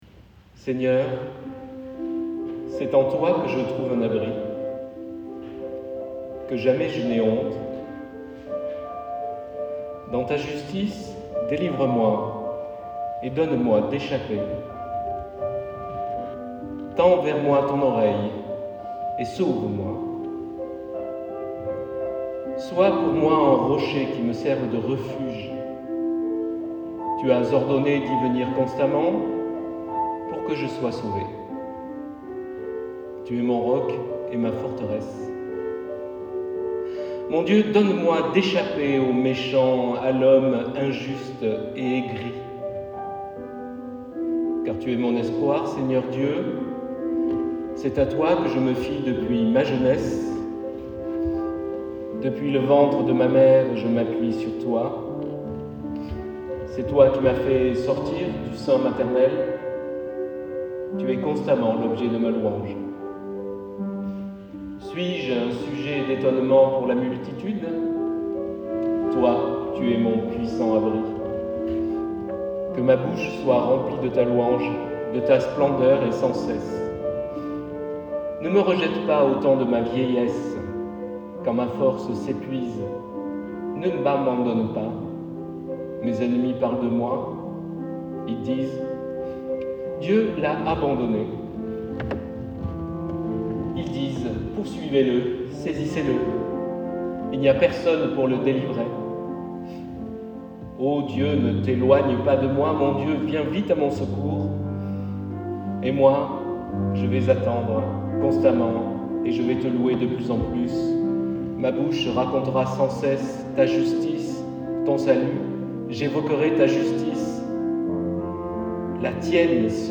extraits du culte du 5 janvier 2025.mp3 (68.4 Mo)
PIANO
SUR PIANO DOUX ACCOMPAGNANT (voire rythmant)